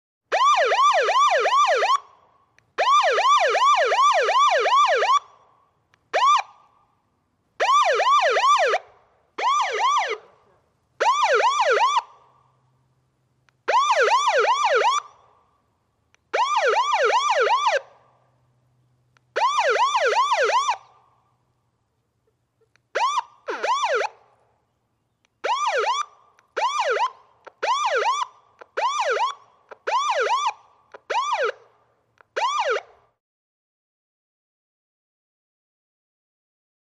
Police Whooper Siren, Short Bursts Close.